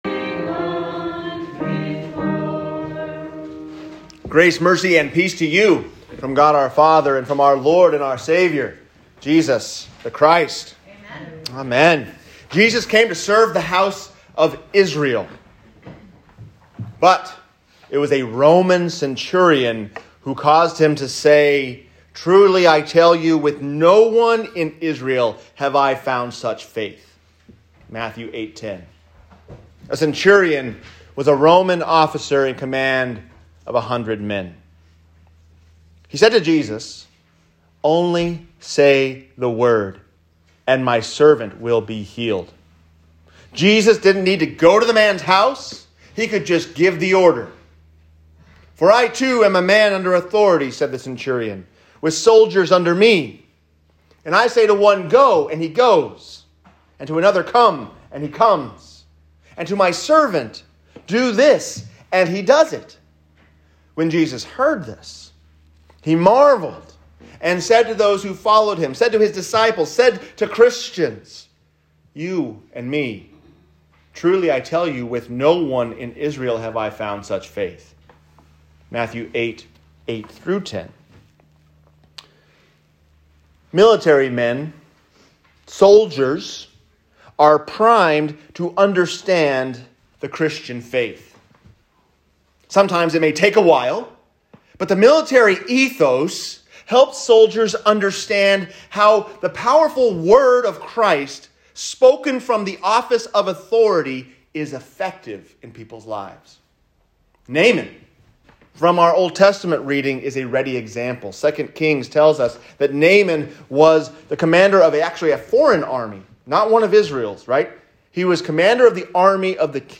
Faith Like A Soldier | Sermon